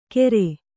♪ kere